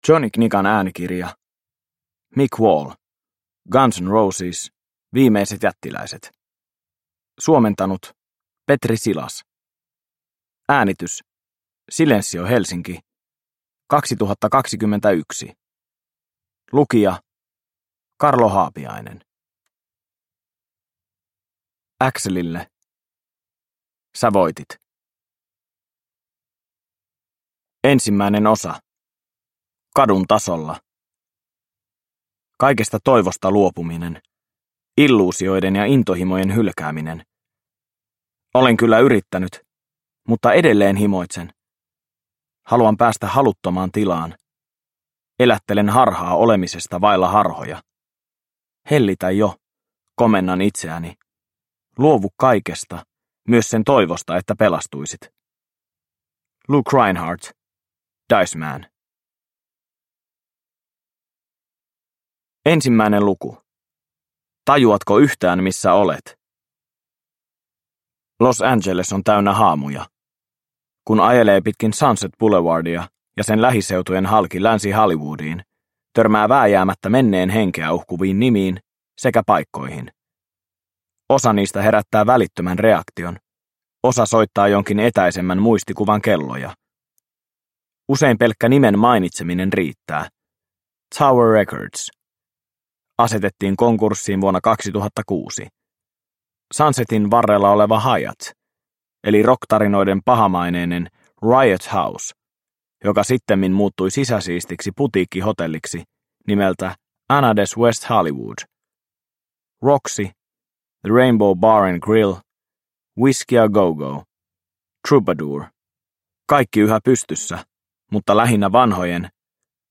Guns N' Roses – Ljudbok – Laddas ner